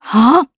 Isolet-Vox_Casting3_jp.wav